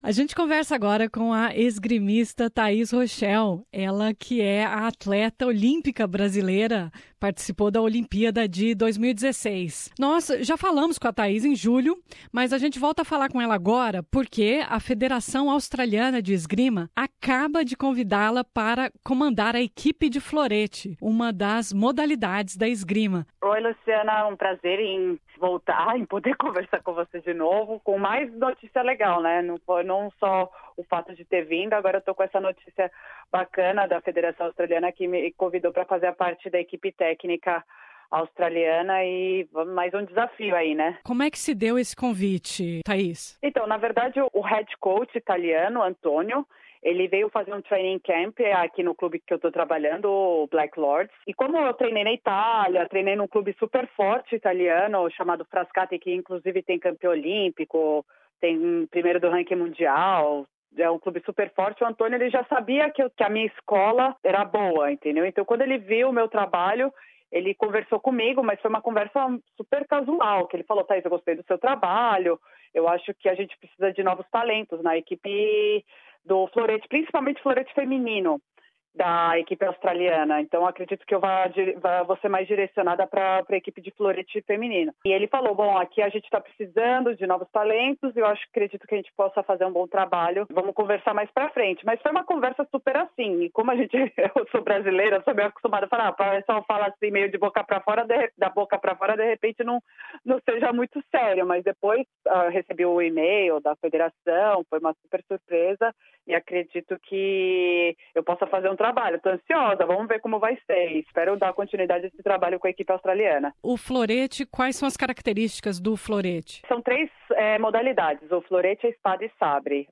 Nessa entrevista ela fala do convite, de como combina o trabalho de técnica com o treino de atleta e dos preparativos para as Olimpiadas de Tóquio.